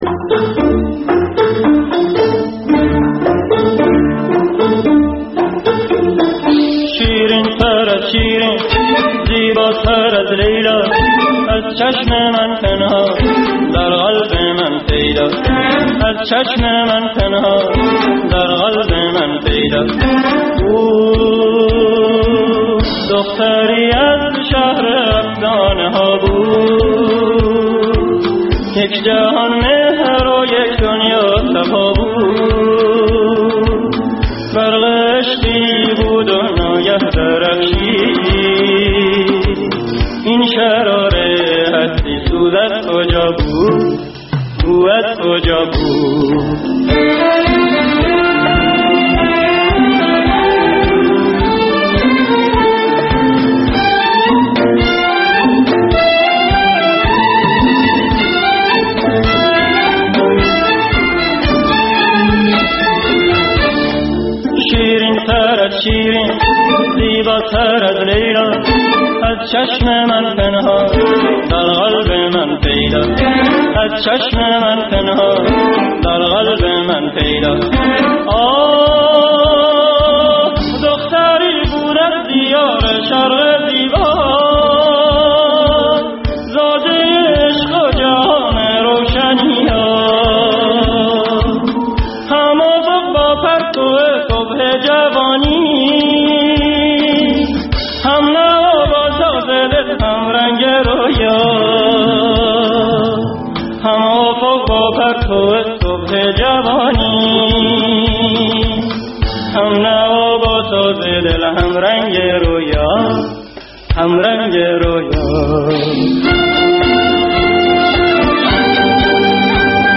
پنجشنبه‌ها از ساعت هشت شب به مدت دو ساعت با برنامه زنده موسیقی رادیو فردا همرا ه باشید.